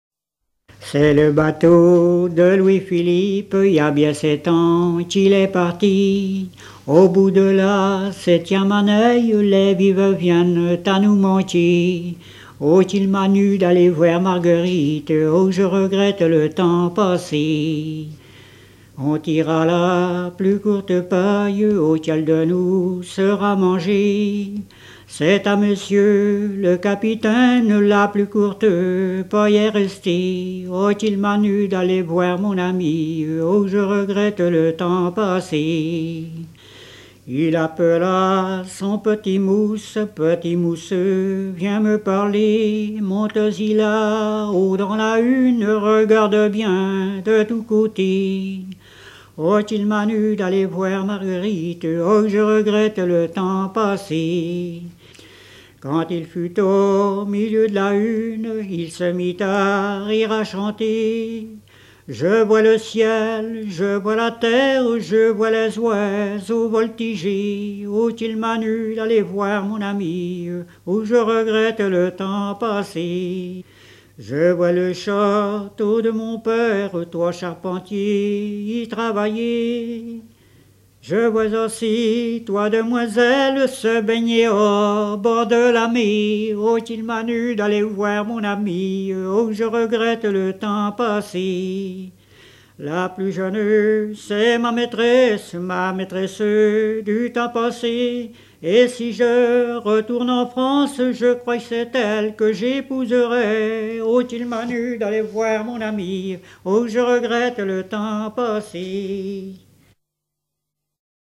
Enregistré en 1979
danse : ronde à trois pas
Genre laisse